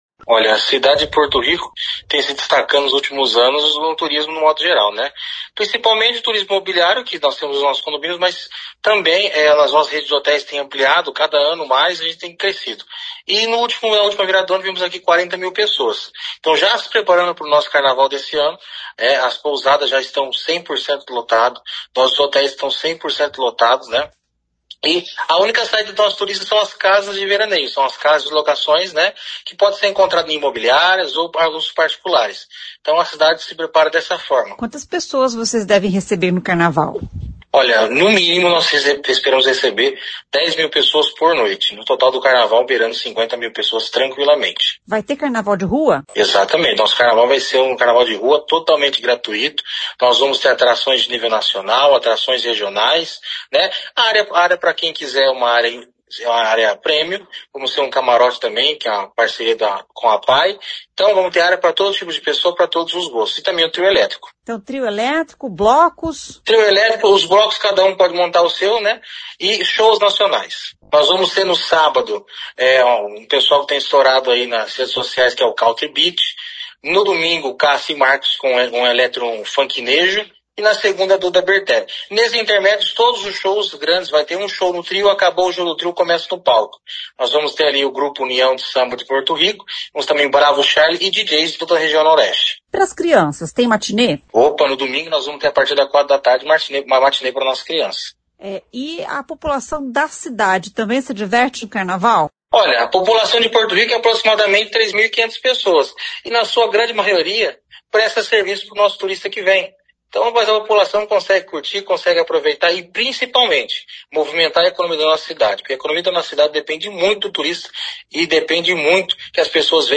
O secretário Guilherme Vaceli fala sobre o assunto.